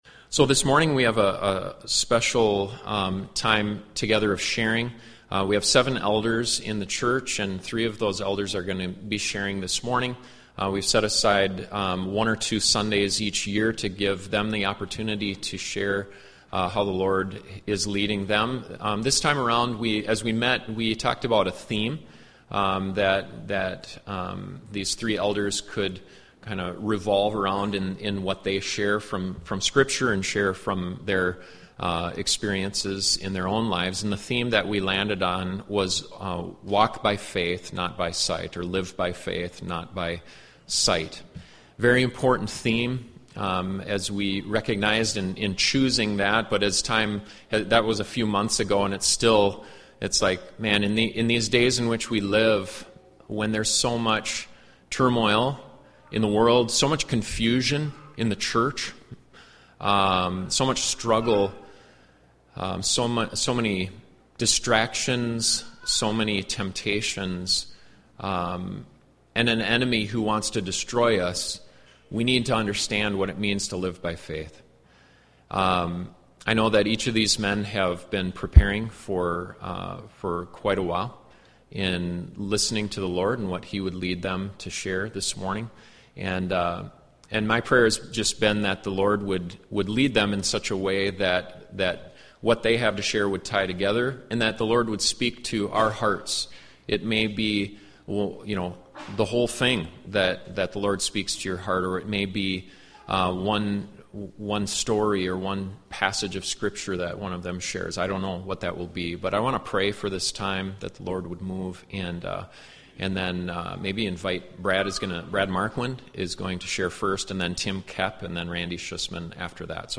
Three of the East Lincoln elders share insight and teaching about walking by faith, not by sight.